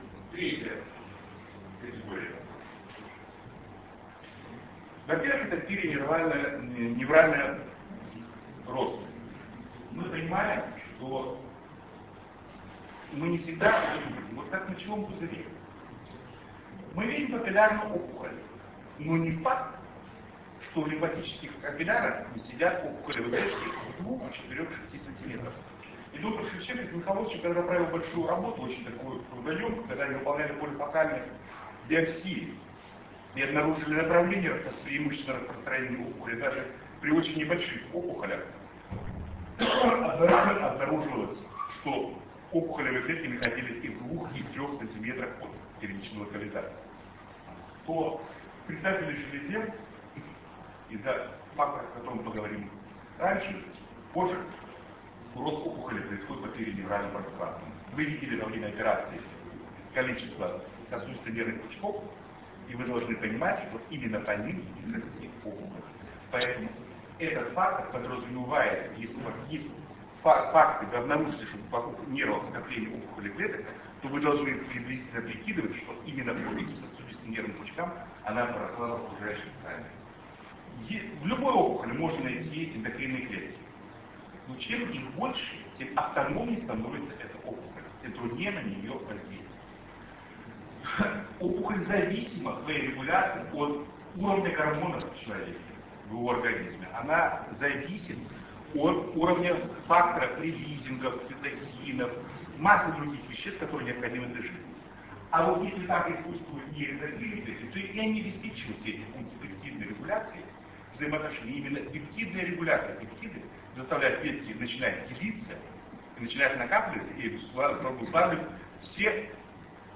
Съезд Ассоциации Урологов Дона с международным участием. Ростов-на-Дону, 27-28 октября 2004 года.
Лекция: "Прогностические факторы при раке предстательной железы".